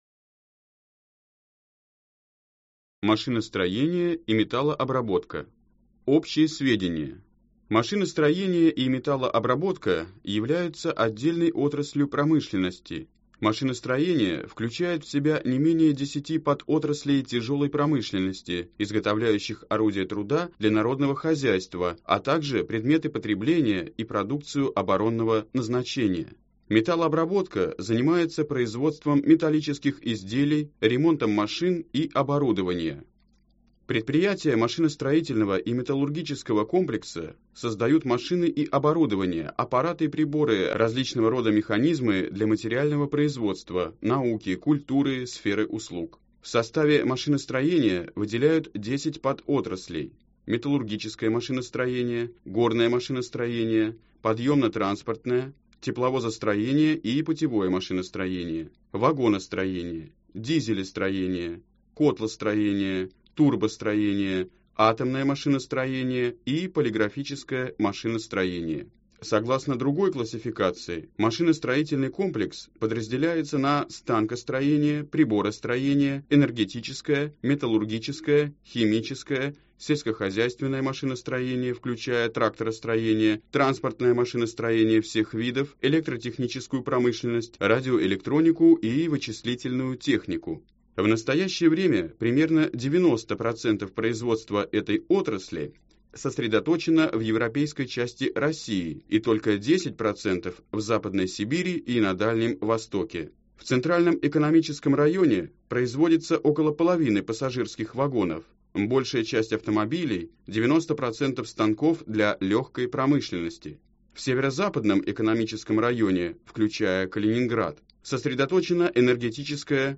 Аудиокнига Современная Россия | Библиотека аудиокниг